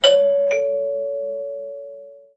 大门铃
描述：大门铃的叮咚声。
标签： 门铃
声道立体声